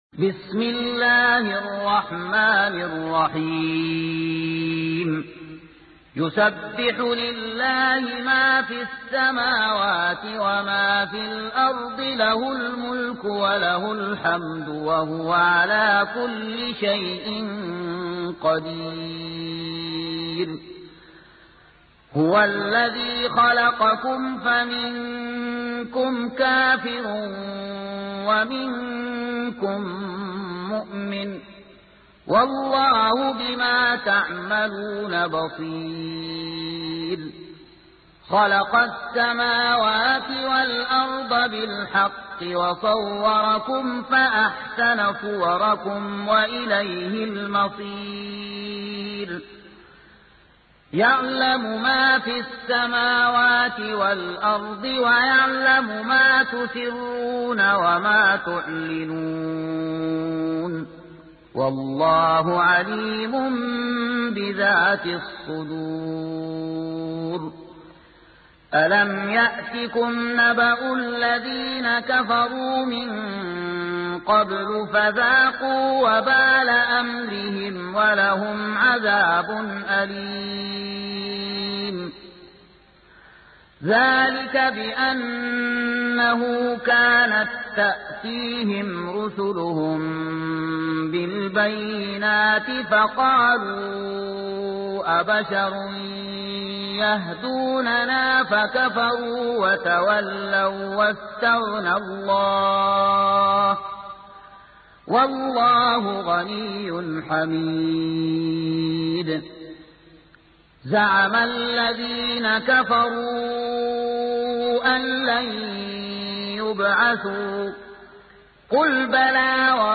سورة التغابن | القارئ